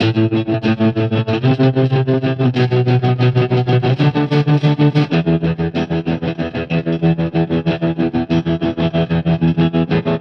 Trem Trance Guitar 01e.wav